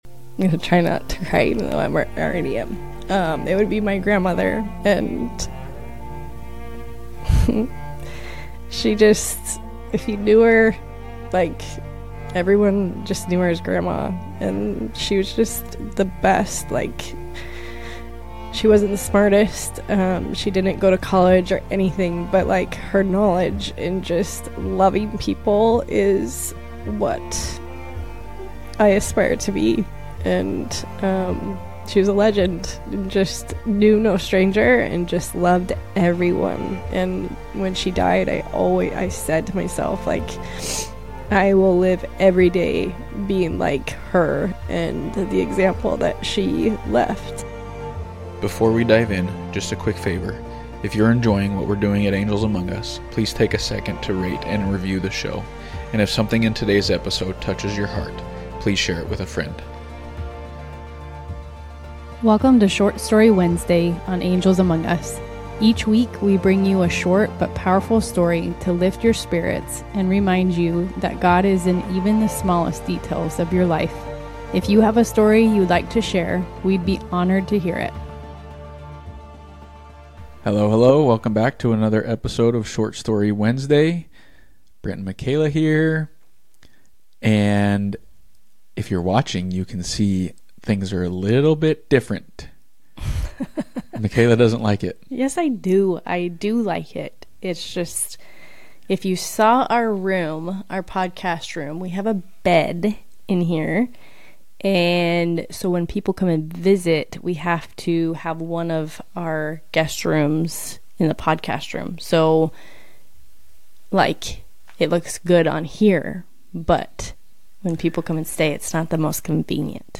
Fun and honest couple Q&A